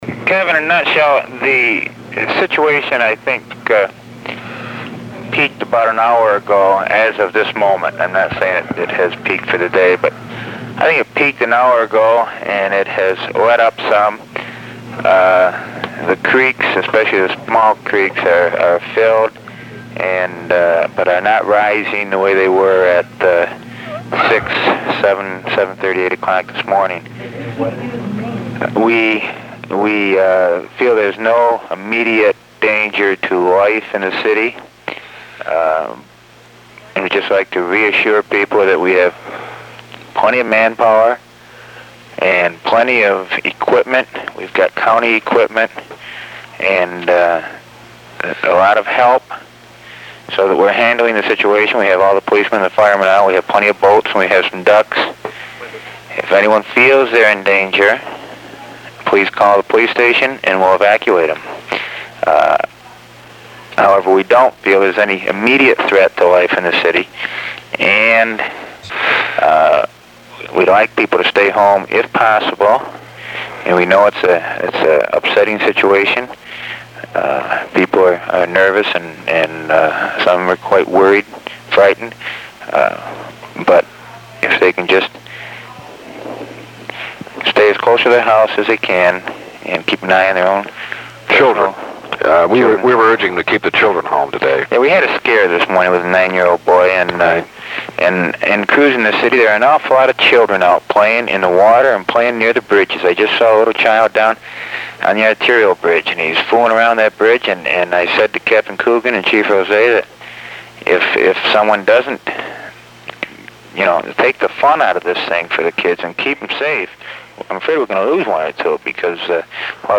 Flood of 1972: Mayor Mazella Talking